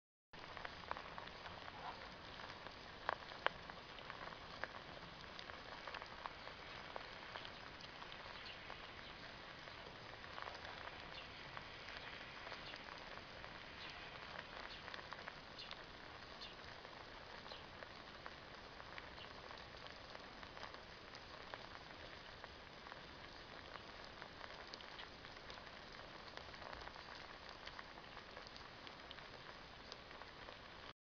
На этой странице собраны разнообразные звуки майского жука: от характерного жужжания до шума крыльев в полете.
Множество майских жуков копошится в коробке